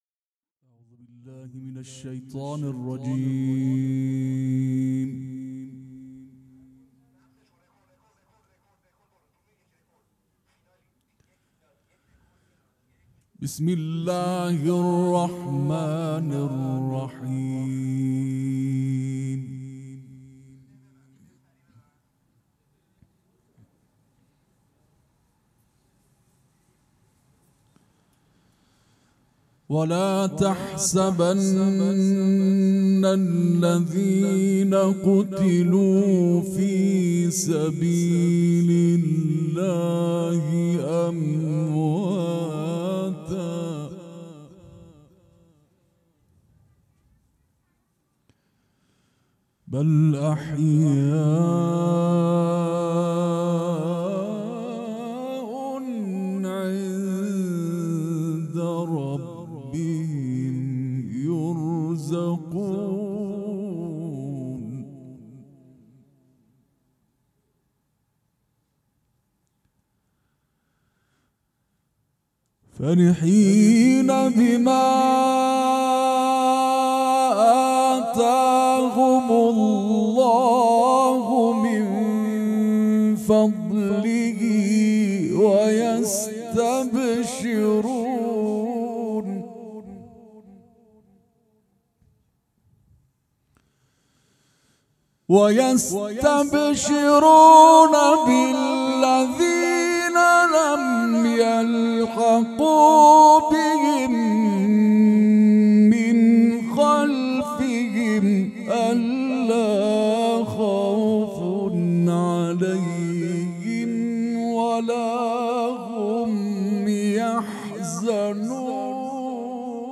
قرائت قرآن